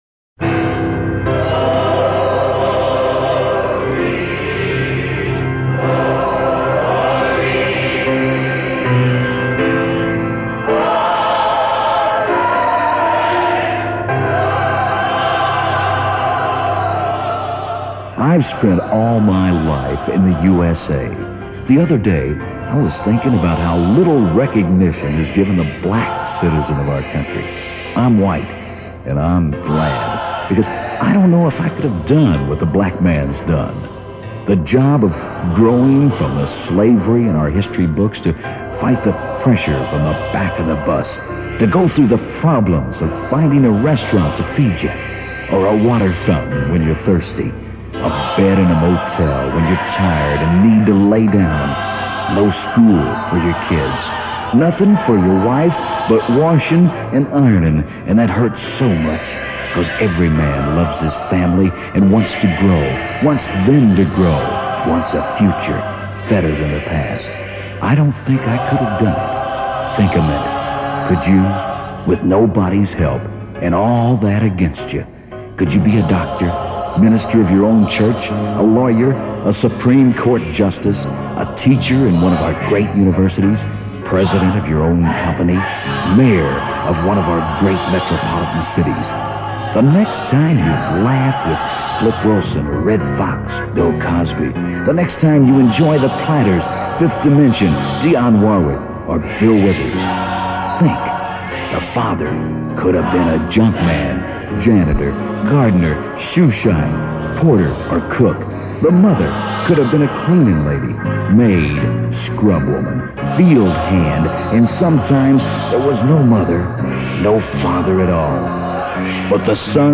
gospel choir wailing